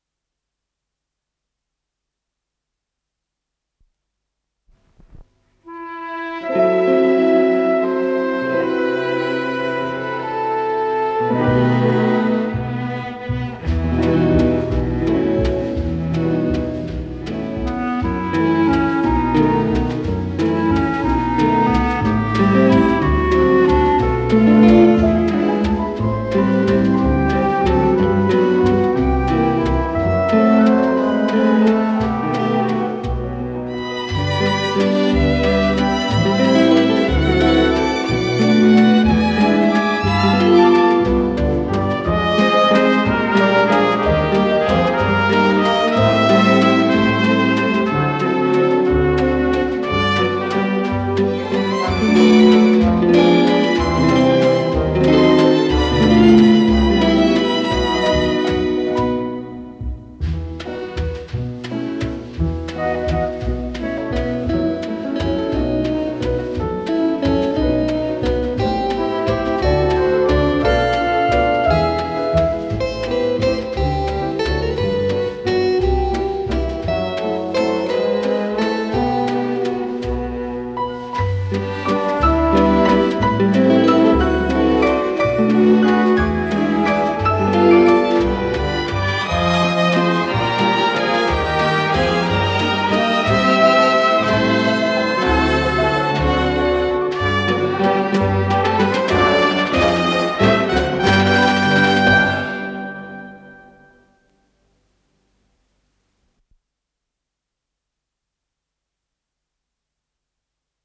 с профессиональной магнитной ленты
ПодзаголовокЗаставка, соль мажор
ВариантДубль моно